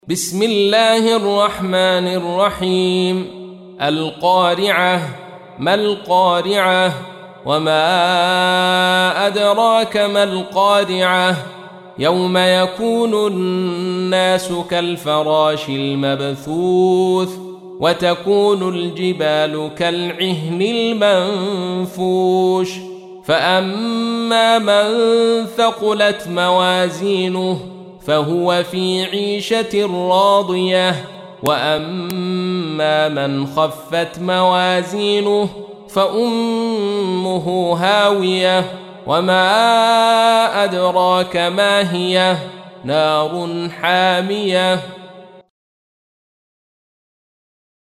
تحميل : 101. سورة القارعة / القارئ عبد الرشيد صوفي / القرآن الكريم / موقع يا حسين